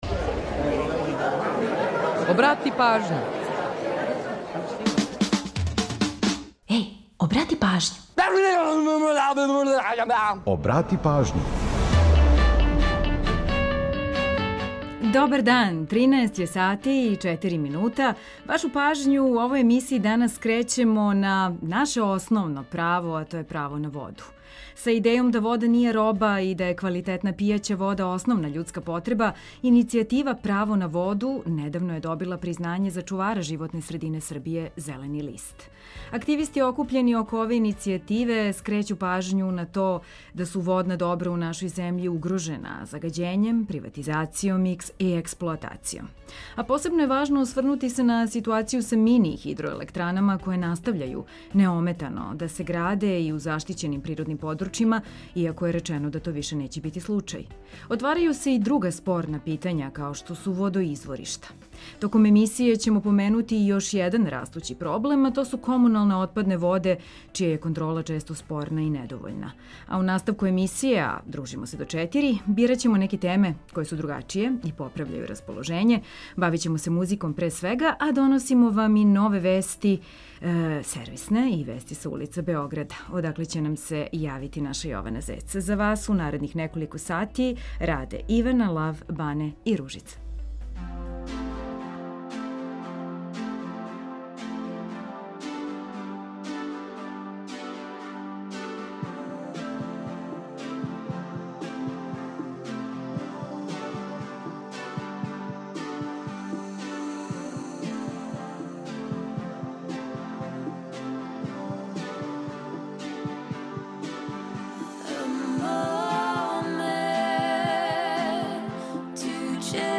Бавићемо се музиком, пре свега.